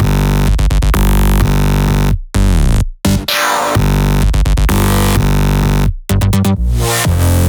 VDE 128BPM Silver Melody 2 Root G.wav